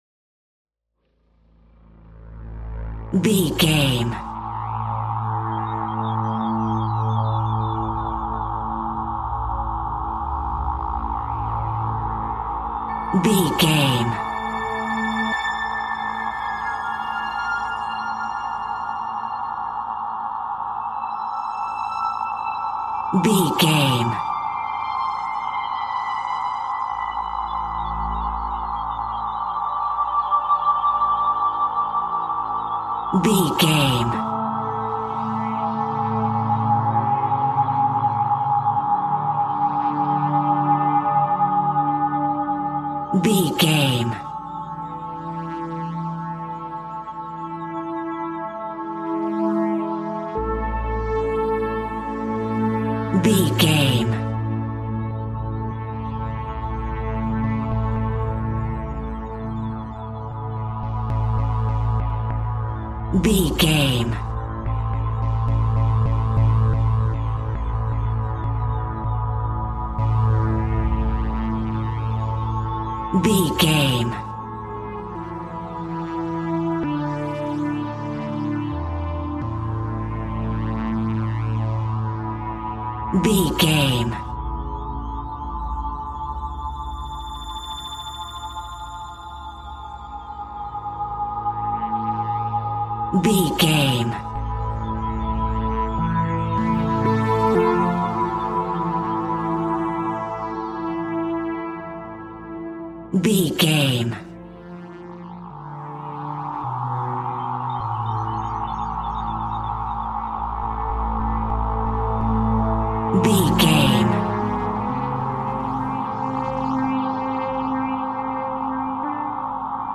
Dark Killer Music.
In-crescendo
Aeolian/Minor
Slow
tension
ominous
haunting
eerie
strings
synth
ambience
pads